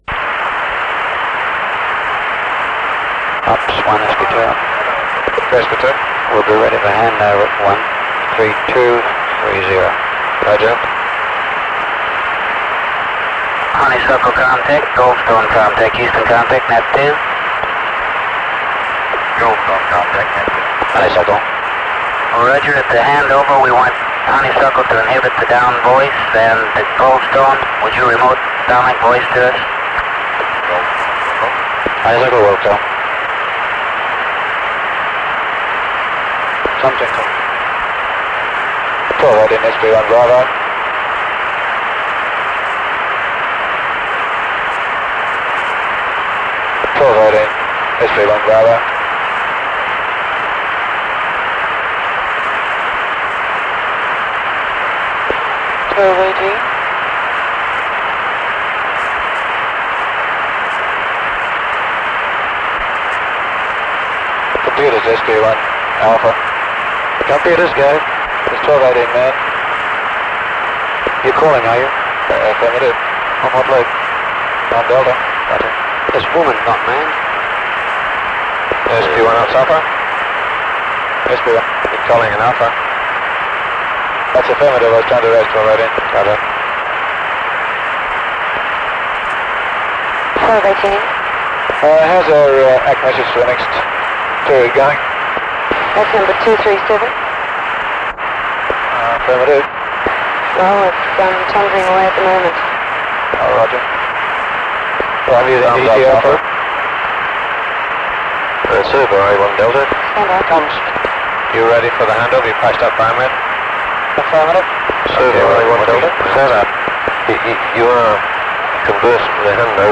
Honeysuckle’s last track of Apollo 13, as the spacecraft nears Earth on 17 April 1970.
This recording of Net 2 plus several internal Honeysuckle comms loops begins at 132:27GET.